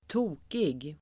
Uttal: [²t'o:kig]